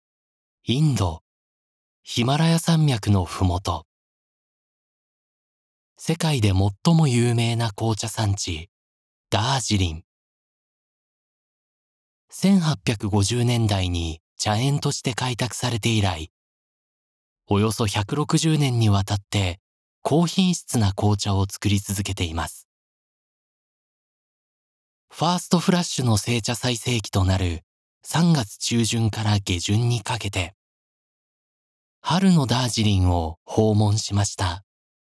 Vielseitige, zuverlässige, warme und beruhigende Stimme, die sich für Geschäfts-, Werbe- und Dokumentarfilme eignet.
Sprechprobe: Industrie (Muttersprache):
Japanese voice over artist. Versatile, reliable, warm and soothing voice suitable for business, commercials and documentaries.